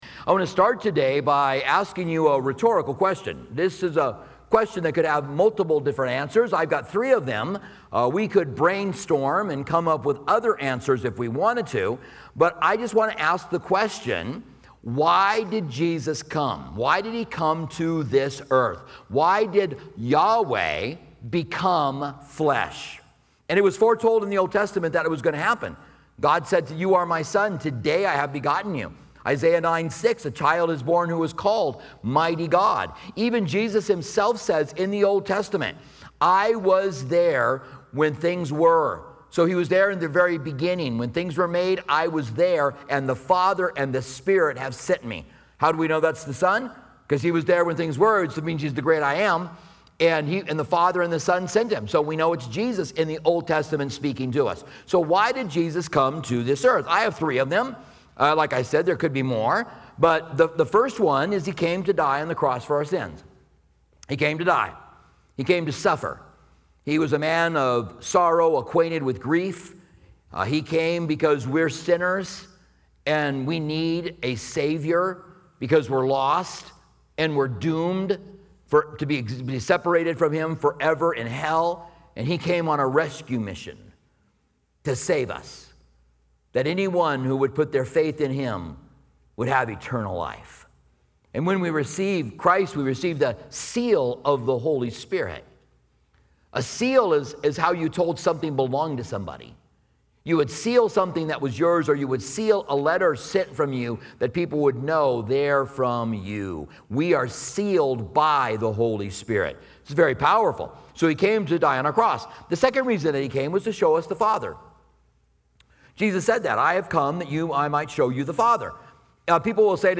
This sermon explores the profound message of Jesus' mission to seek and save the lost, highlighting His compassion for the marginalized, brokenhearted, and sinners. Discover why Jesus chose to reach out to figures like Levi (Matthew), the significance of His call to repentance, and how we can live out His mission by connecting with those who are often overlooked by society.